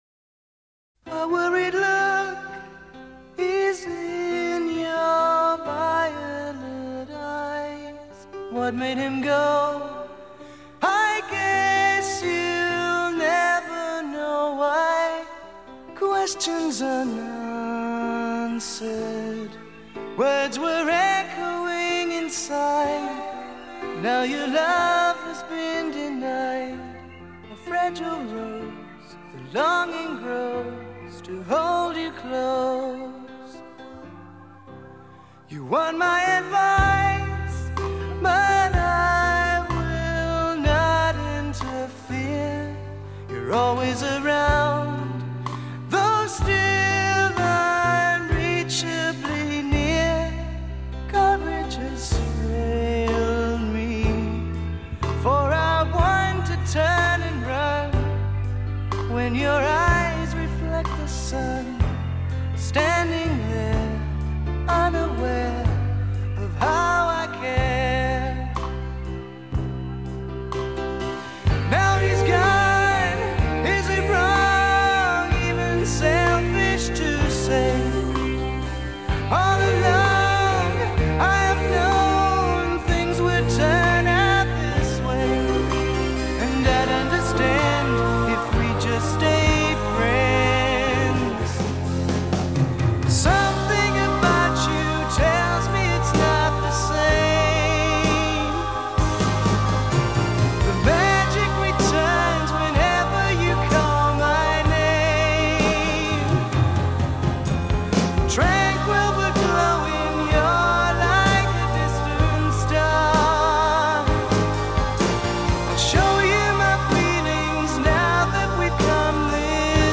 sublime ballad
rock bands